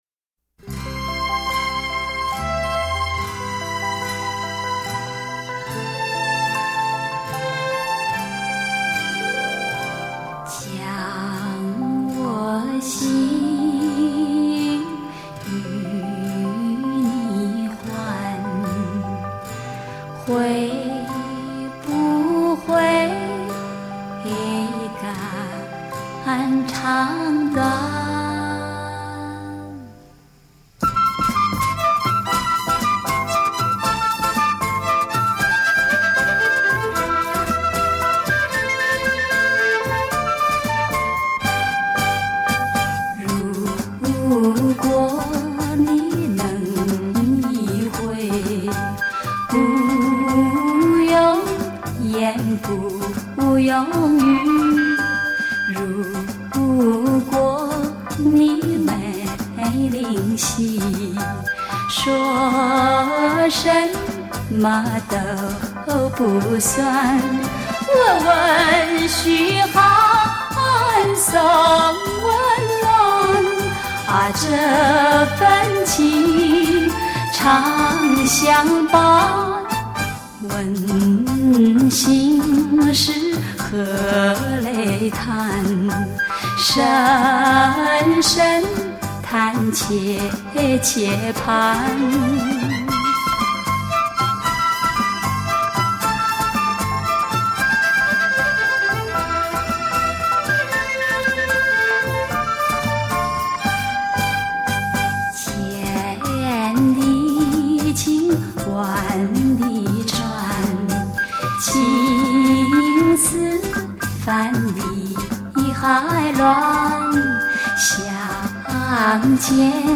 本套全部歌曲皆数码系统重新编制
令音场透明度及层次感大为增加
并使杂讯降为最低